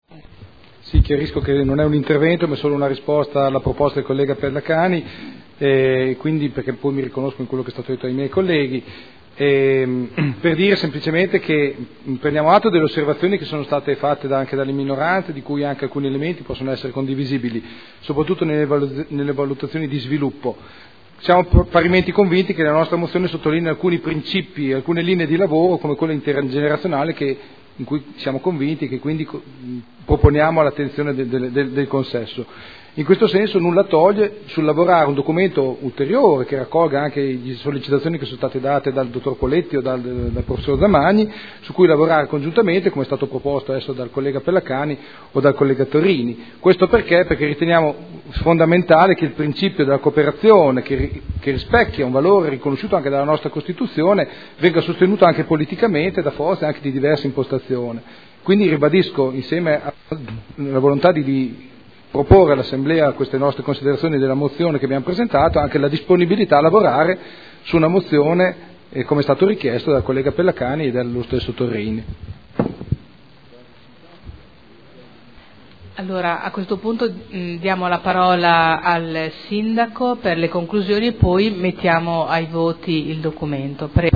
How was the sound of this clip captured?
Seduta del 22/11/2012. Dibattito su celebrazione dell’Anno internazionale delle cooperative indetto dall’ONU per il 2012